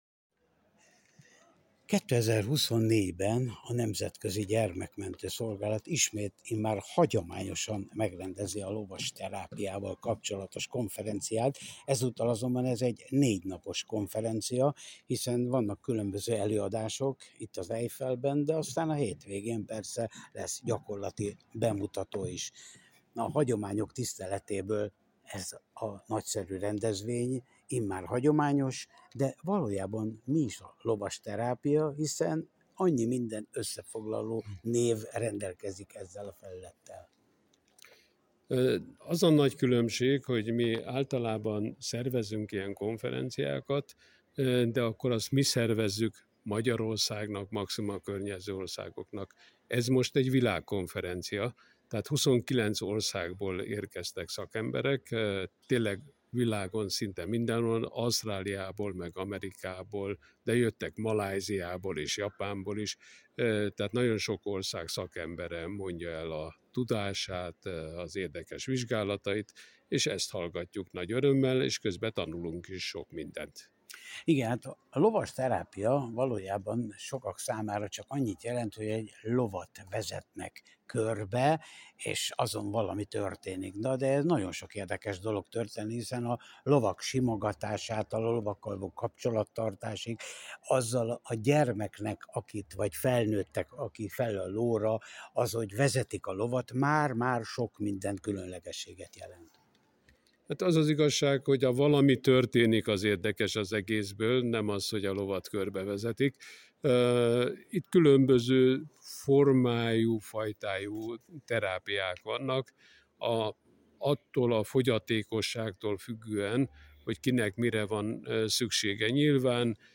akivel készült interjú mellékelten olvasható